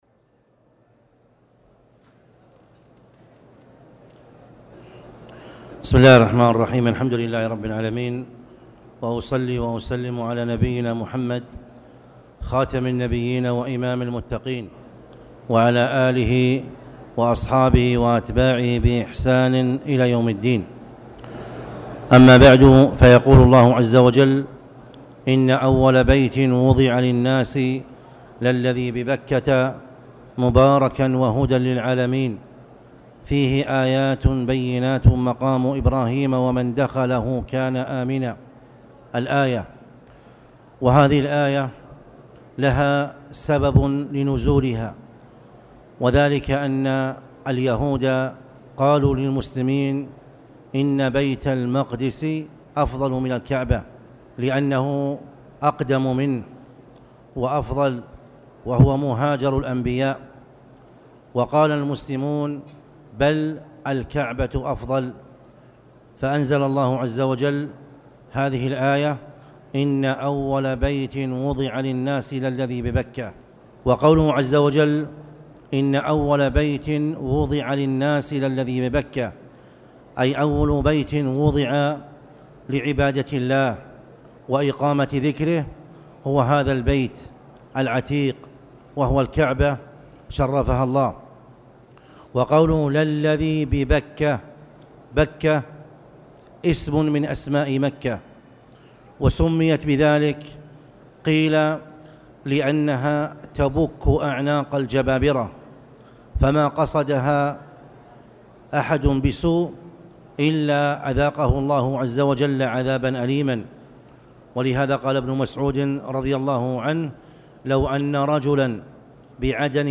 المكان: المسجد الحرام
29jmada-alawla-ktab-aljnayz-bad-almghrb.mp3